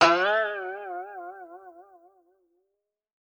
Boing (6).wav